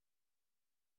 silence.mp3